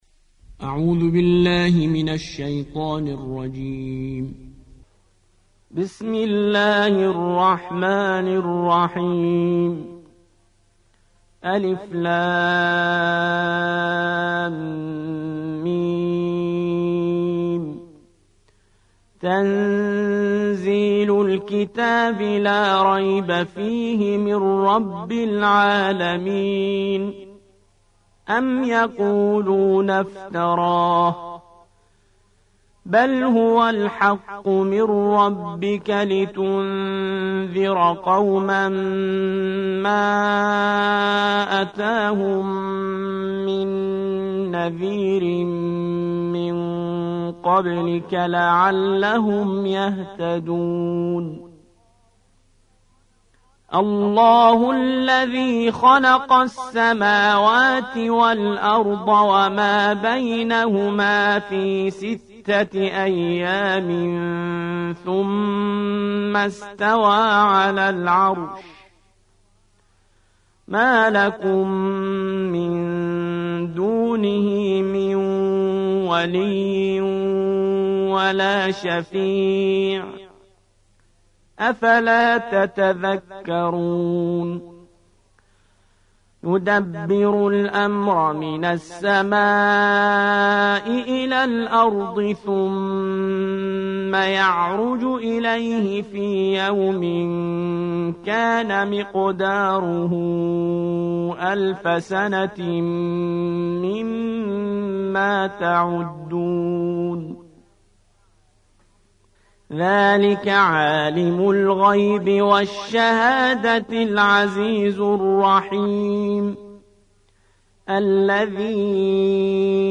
32. سورة السجدة / القارئ